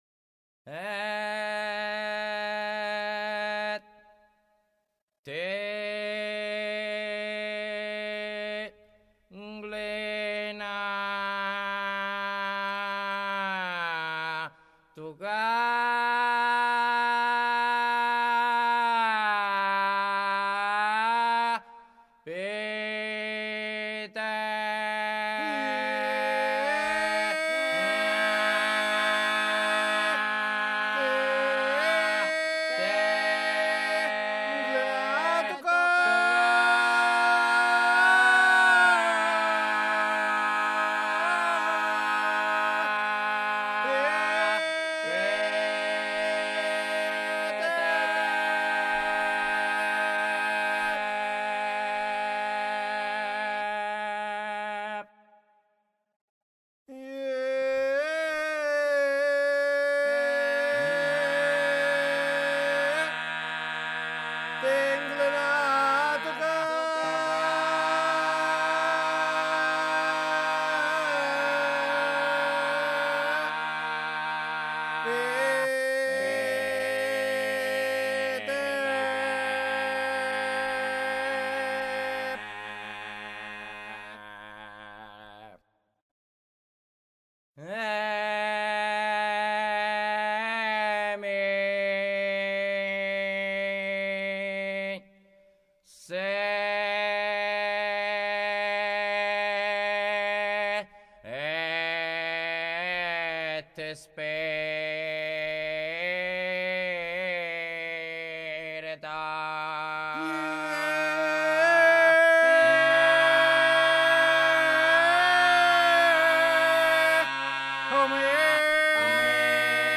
Protagonisti di un tempo erano li lamentatura, un gruppo di persone che levavano lugubri lamenti di commiato per la morte di Cristo, misti a versi.
In sottofondo le musiche solenni e lugubri della banda e le litanie funebri dei lamentatura che perdurano fino alla mezzanotte, l’ora che conclude le cerimonie del Venerdì Santo con la spartenza, cioè la separazione.
Lamentatori.wma